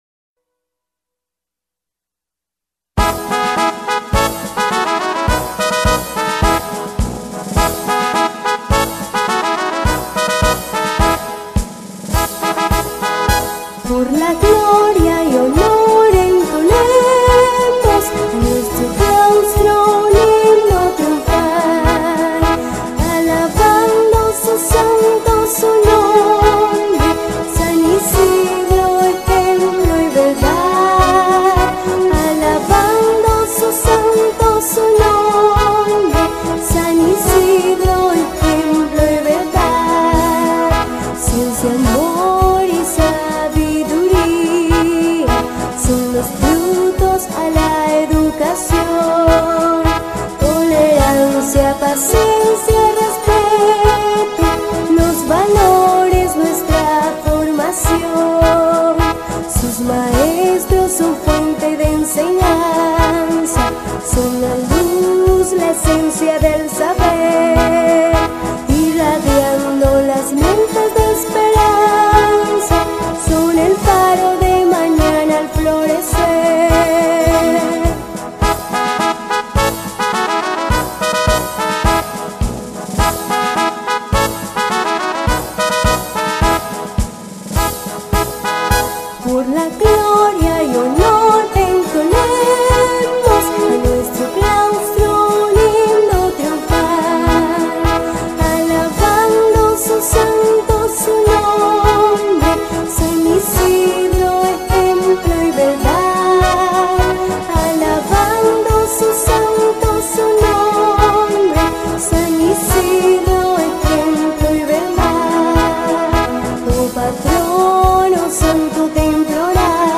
himno_IE_San_Isidro.mp3